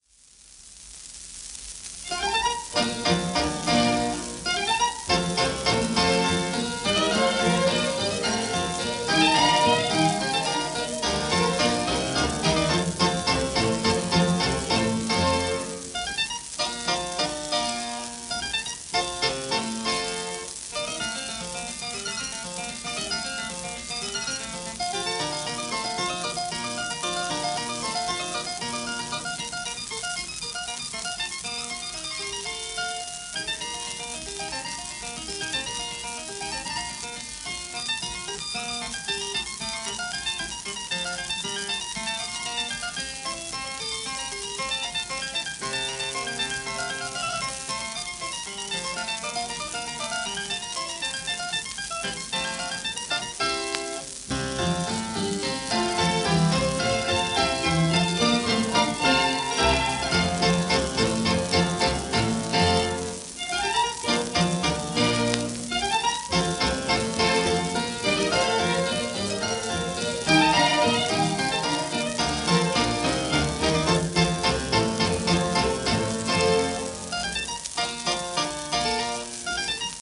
、オーケストラ、不詳 cem.
盤質A- *キズ、軽い盤反り(キズ部分2箇所、ともに３〜５回クリック音あり)